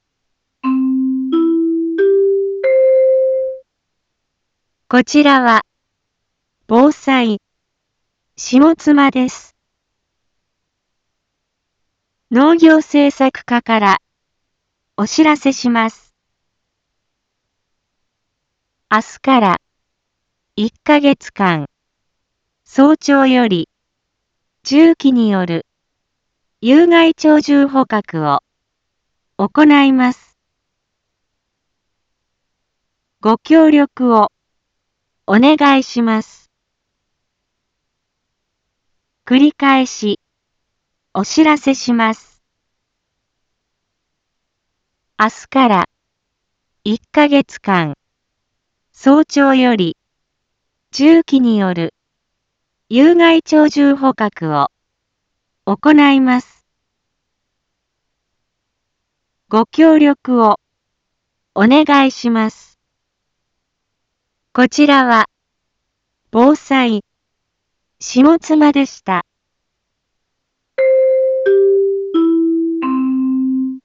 一般放送情報
Back Home 一般放送情報 音声放送 再生 一般放送情報 登録日時：2024-05-24 07:06:16 タイトル：有害鳥獣捕獲の実施についてのお知らせ インフォメーション：こちらは、ぼうさい、しもつまです。